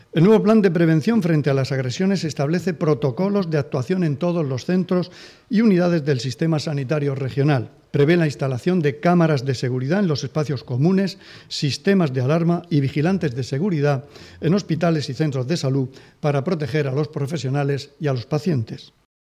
Declaraciones del consejero de Salud, Juan José Pedreño, sobre el nuevo plan de la Comunidad para prevenir agresiones a profesionales sanitarios.